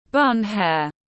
Tóc búi cao tiếng anh gọi là bun hair, phiên âm tiếng anh đọc là /bʌn heər/ .
Bun hair /bʌn heər/